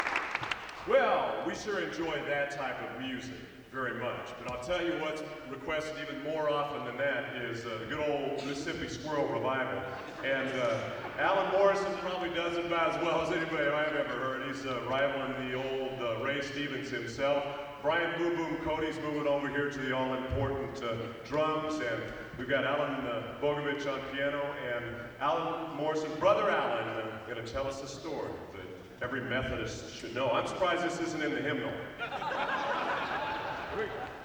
Collection: Broadway Methodist, 1993